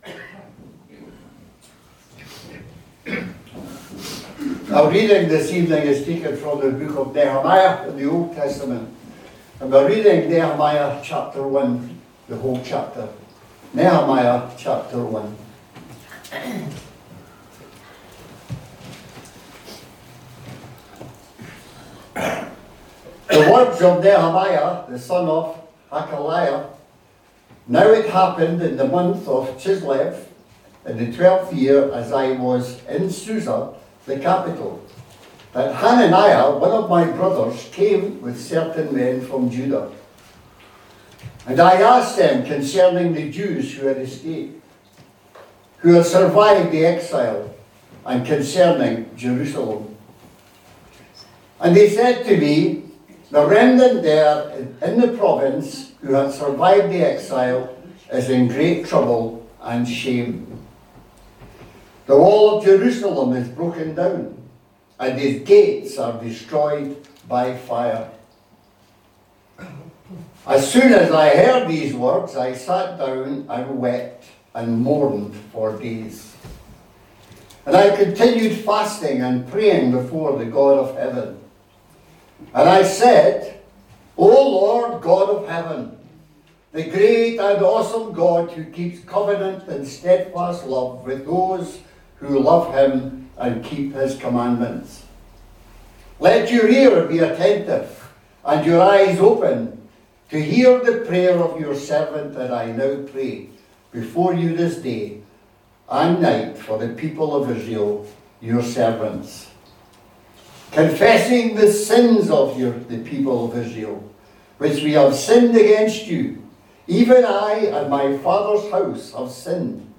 A link to the video recording of the 6:00pm service, and an audio recording of the sermon.
Series: Individual sermons